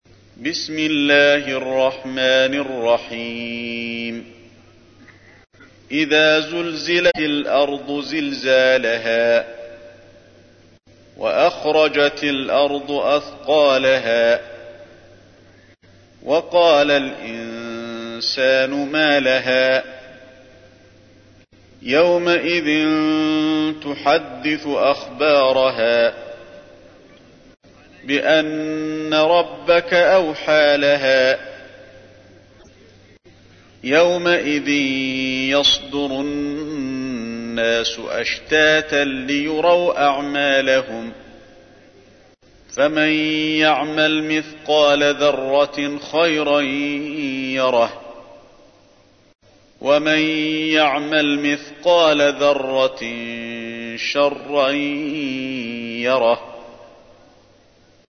تحميل : 99. سورة الزلزلة / القارئ علي الحذيفي / القرآن الكريم / موقع يا حسين